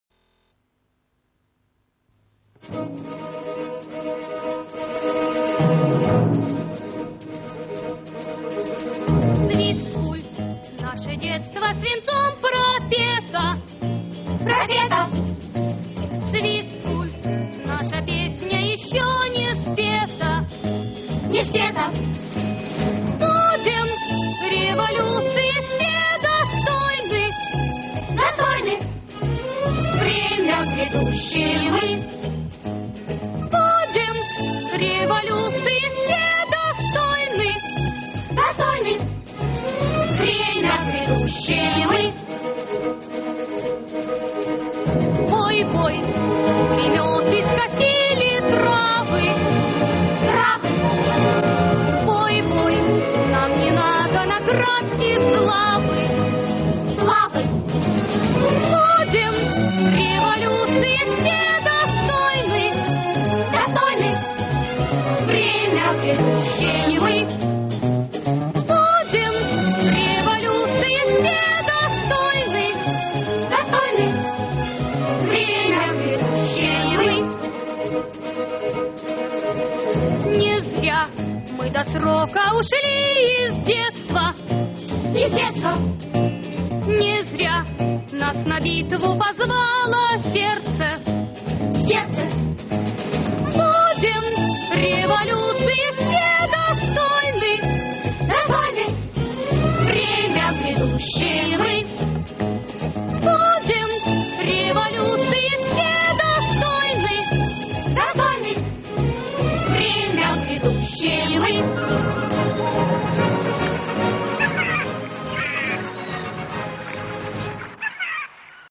Описание: Детская героико-воспитательная.